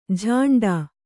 ♪ jhāṇḍā